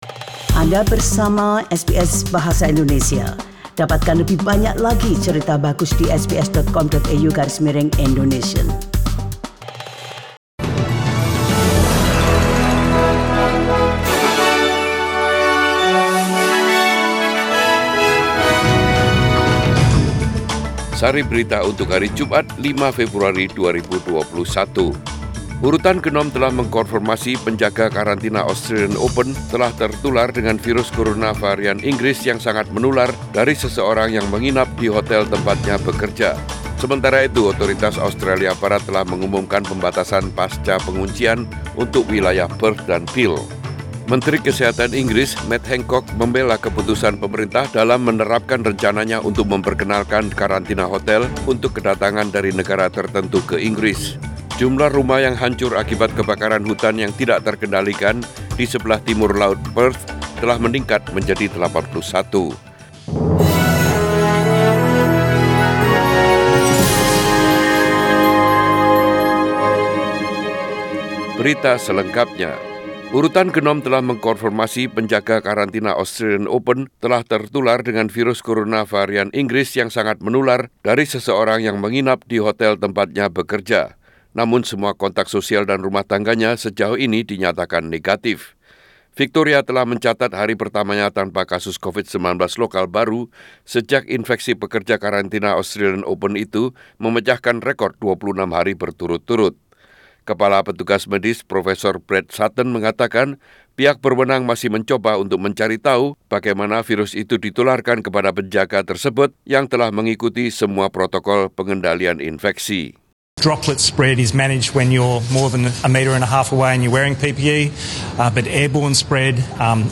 Radio News in Bahasa Indonesia - 5 Fewbruary 2021